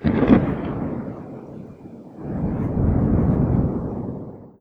tenkoku_thunder_distant02.wav